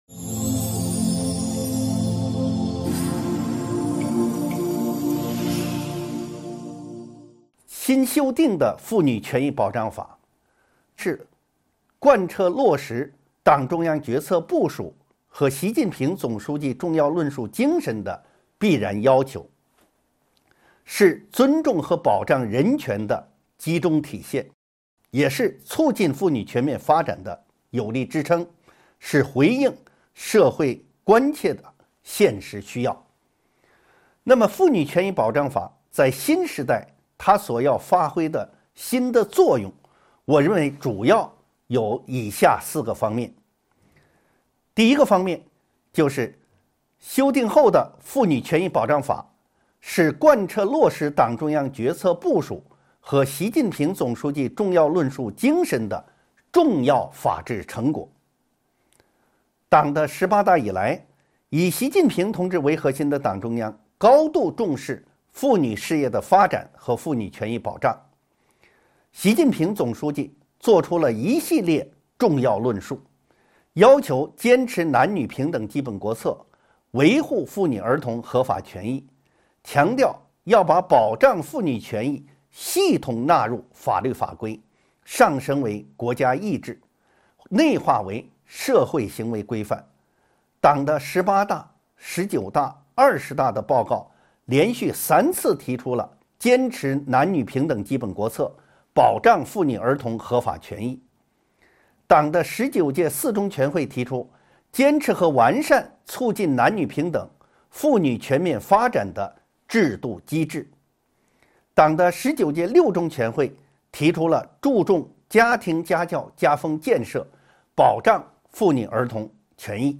音频微课：《中华人民共和国妇女权益保障法》2.妇女权益保障法在新时代发挥的新作用